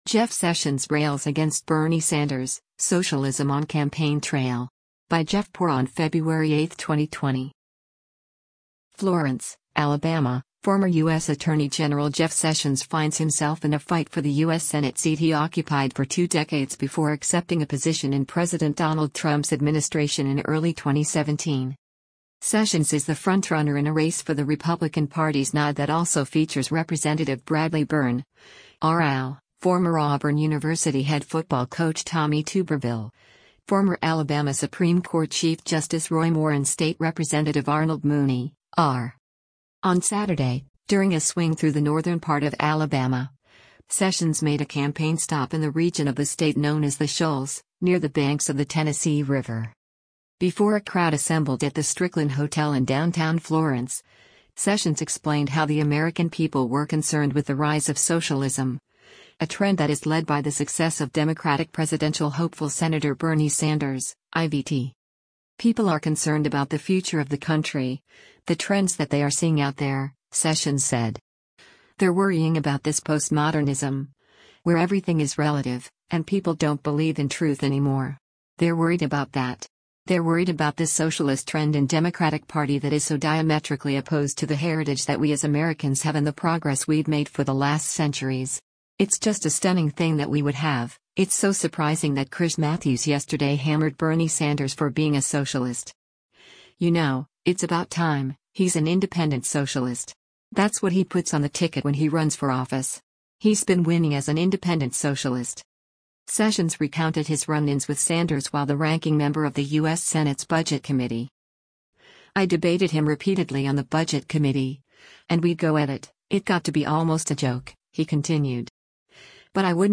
On Saturday, during a swing through the northern part of Alabama, Sessions made a campaign stop in the region of the state known as the Shoals, near the banks of the Tennessee River.
Before a crowd assembled at the Stricklin Hotel in downtown Florence, Sessions explained how the American people were concerned with the rise of socialism, a trend that is led by the success of Democratic presidential hopeful Sen. Bernie Sanders (I-VT).